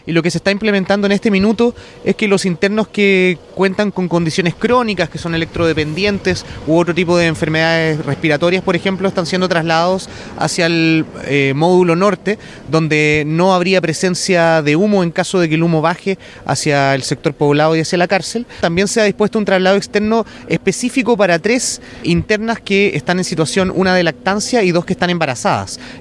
Asimismo, el seremi de Gobierno, Juan Guerra, mencionó que algunos internos de la cárcel de Valdivia fueron cambiados de módulo al interior del mismo recinto penitenciario, mientras que tres mujeres fueron evacuadas a otro recinto de Gendarmería.